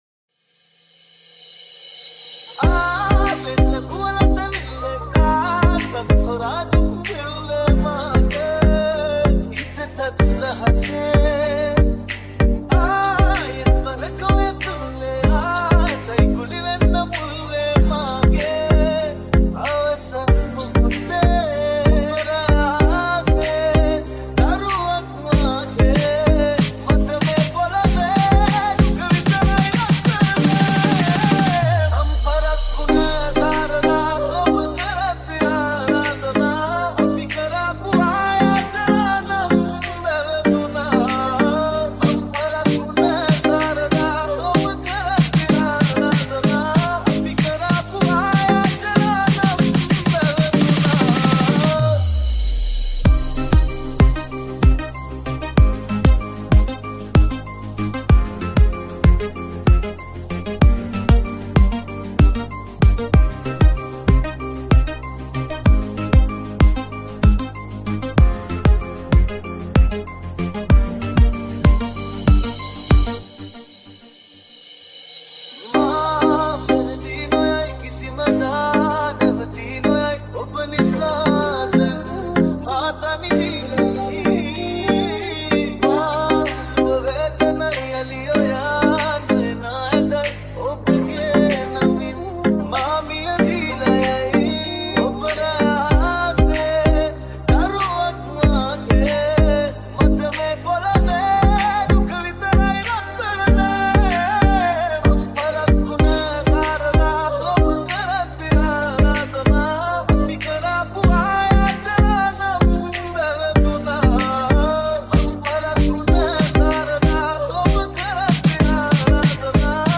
High quality Sri Lankan remix MP3 (3.5).